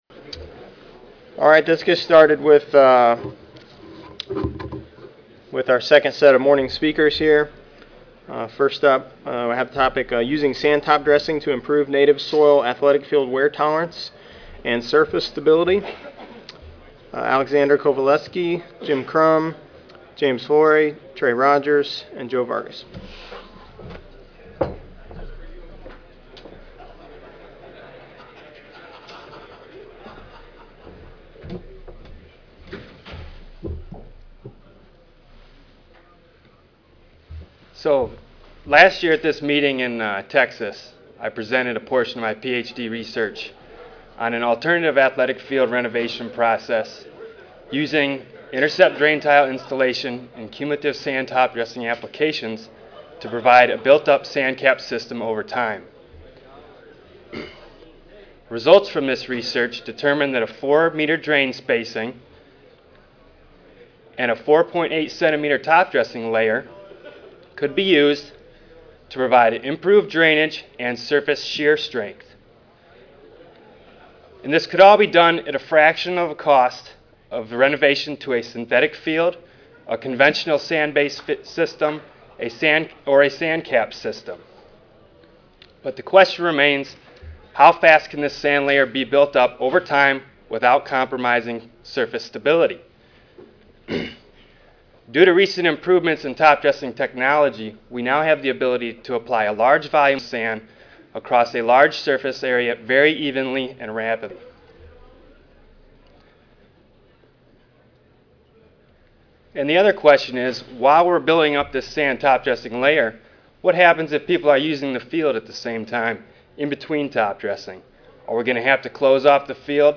See more from this Division: C05 Turfgrass Science See more from this Session: Graduate Student Oral Competition: I